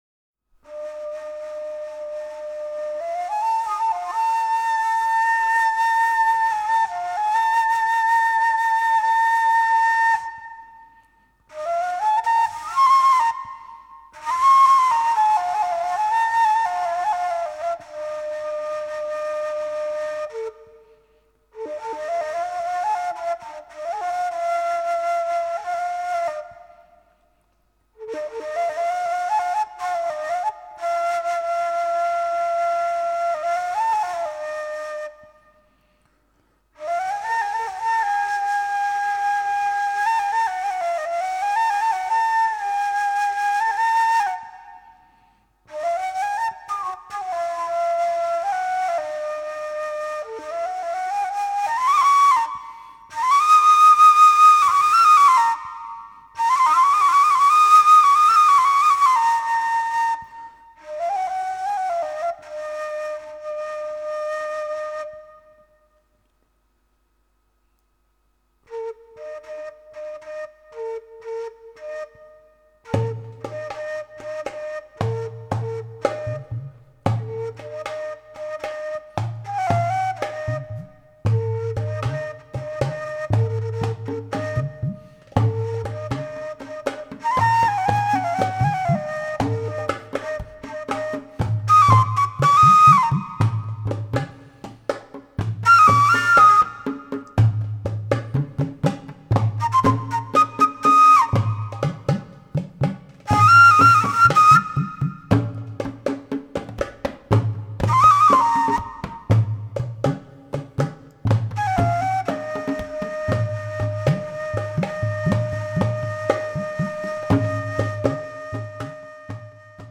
Brisa, Ansam (canción tradicional árabe)